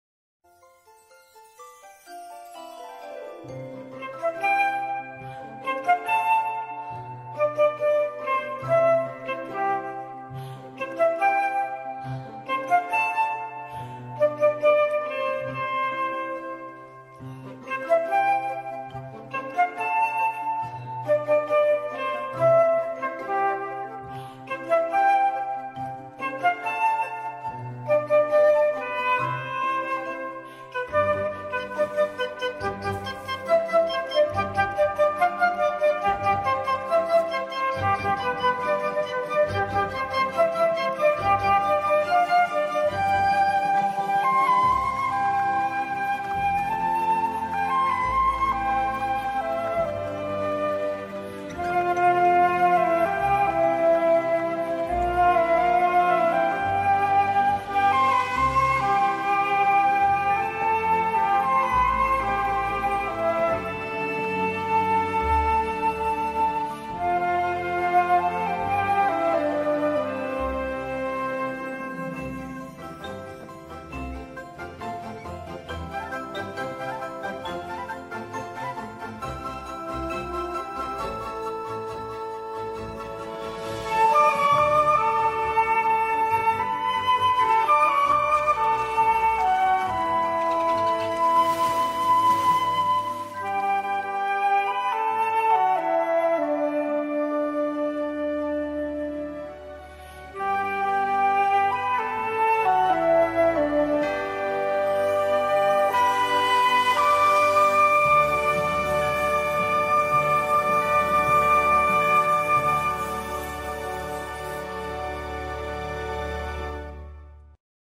flutecover